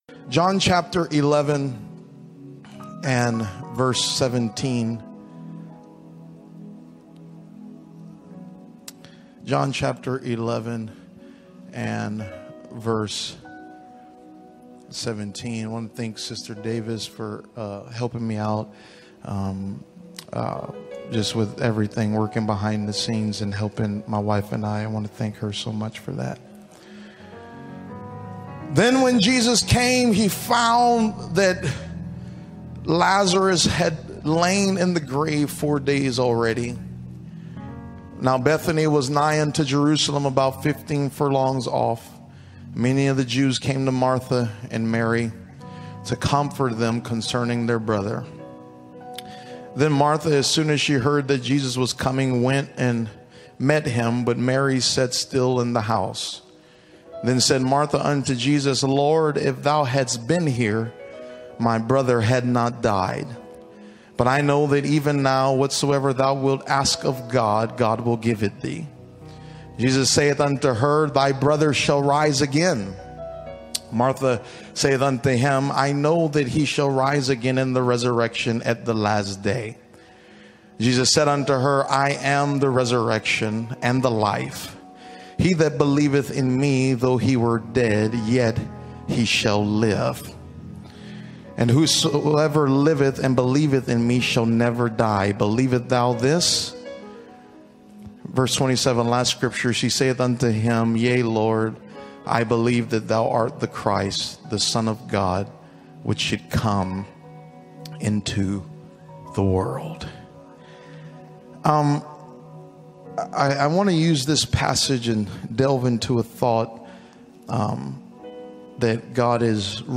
Preaching MP3, The God in graveclothes